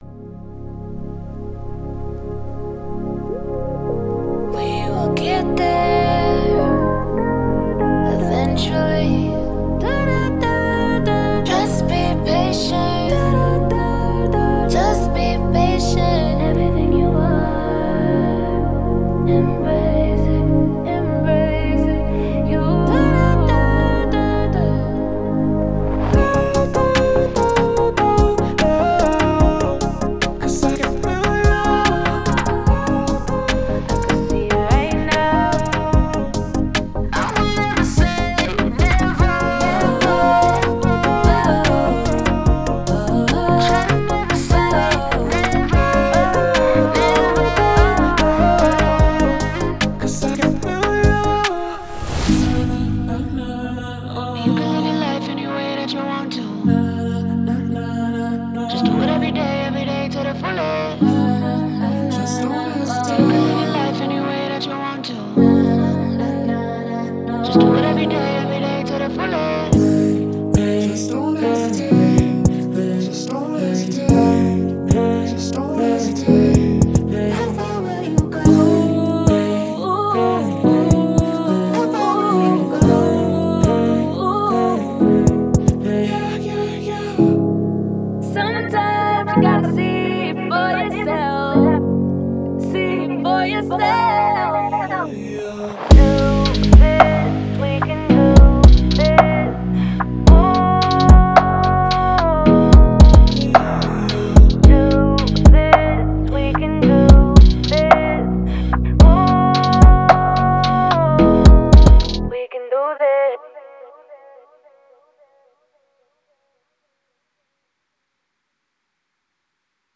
POP
182 LOOPS
27 ONE SHOTS